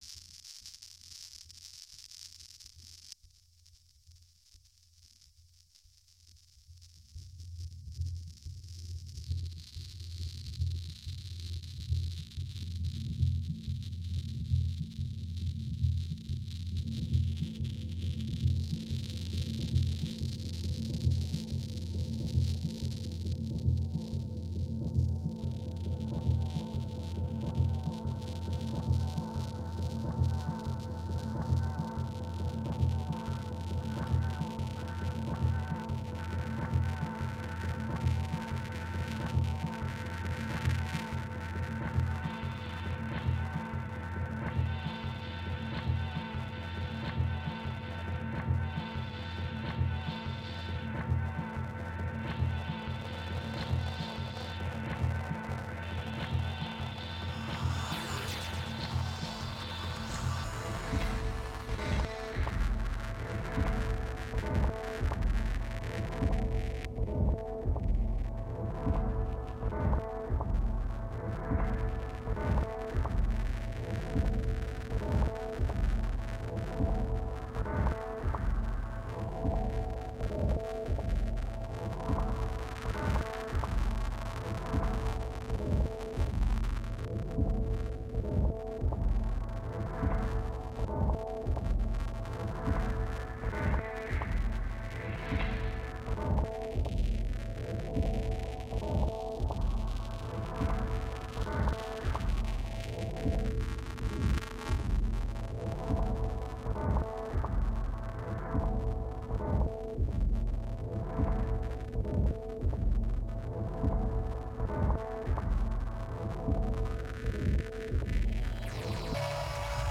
I used a hybrid structure of compound ternary and arch form (only loosely adhered to) for my reimagined piece and all of the sounds within it are derived from the original. These I looped, pitch sifted, reversed and variously processed with filters, flanger, phaser and vocoder. This was all done on hardware, I used no software on the track.